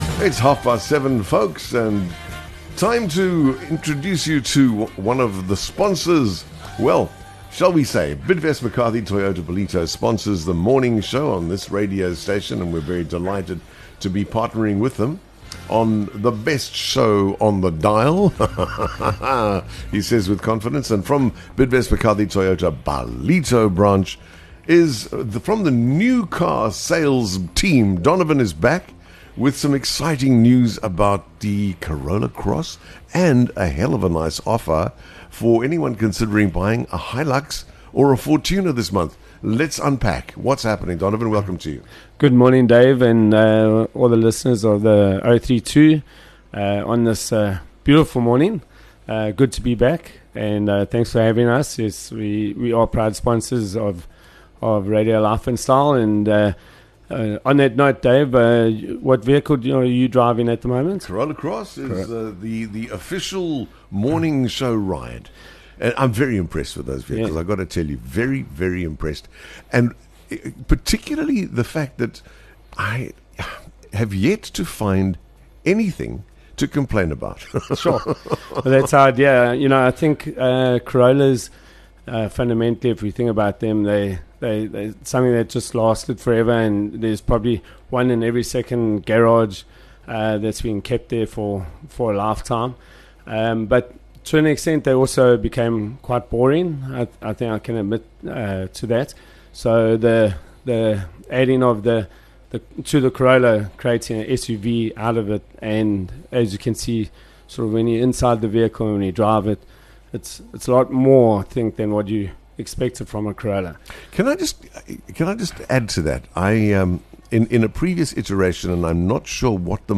Join us for a featured chat with our valued sponsors of the Morning Show – Bidvest McCarthy Toyota Ballito, driving excellence every morning.